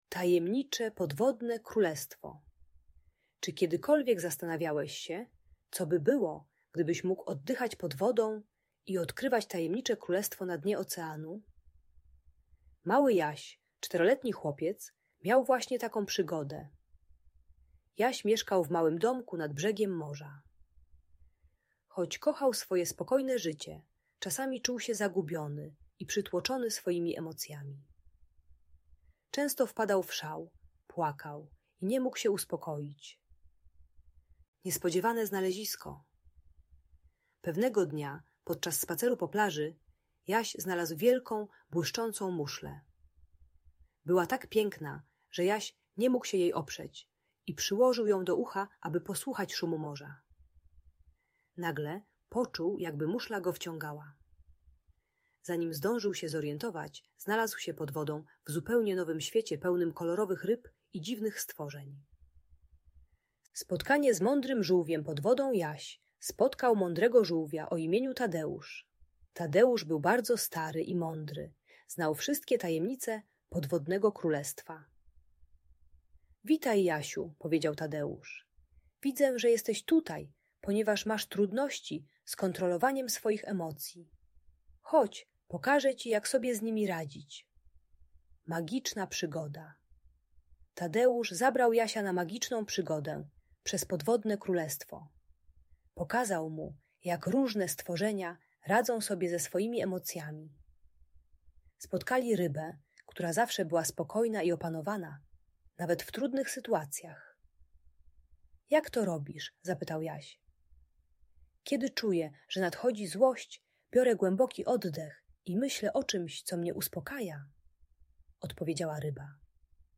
Tajemnicze Podwodne Królestwo - opowieść o emocjach i przygodach - Audiobajka